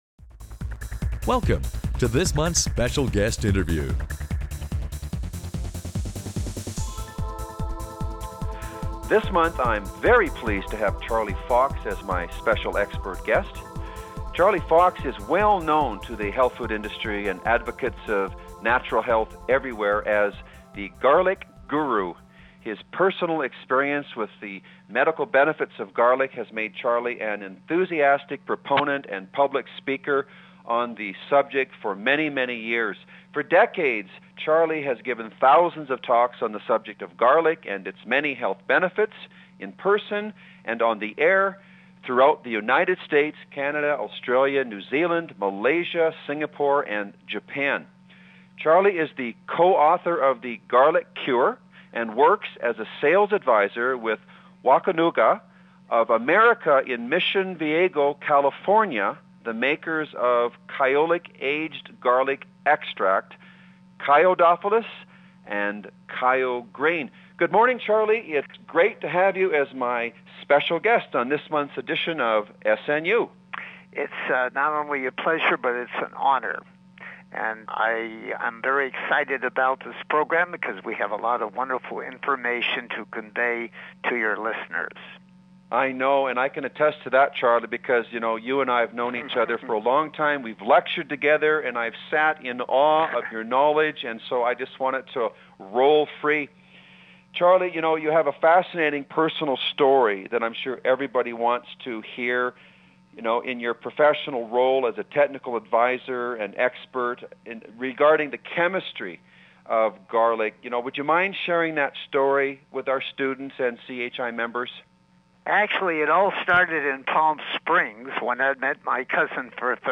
Special Guest Interview Volume 7 Number 7 V7N7c